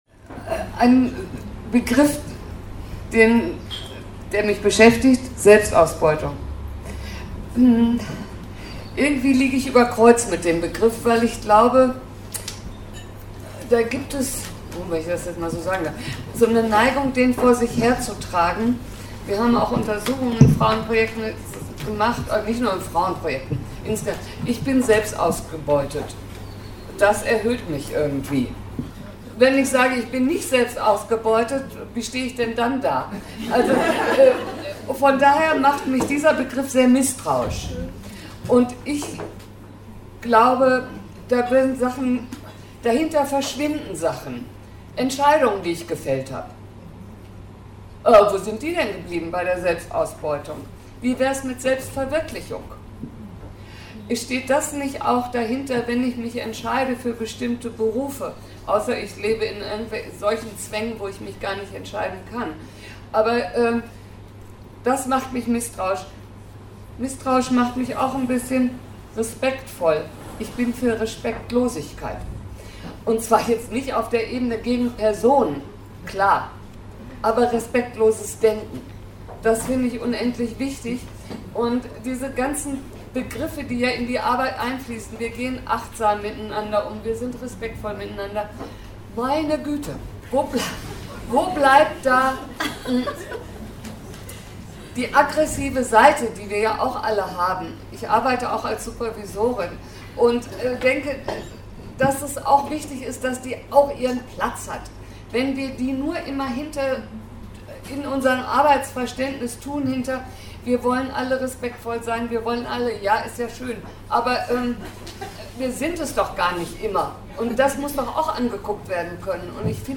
fem*rasant berichtete am 25.7. von der Jubiläumstagung 30 Jahre tifs